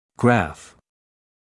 [græf][грэф]кривая; график, диаграмма